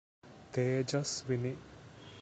pronunciation) is a 1962 Indian Kannada-language film, directed by H. L. N. Simha and produced by Pandari Bai.[1] The film stars Rajkumar, Pandari Bai, Rajashankar and Rajasree.